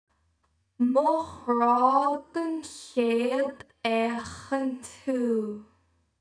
Zumal Sean Nós individuell gesungen wird.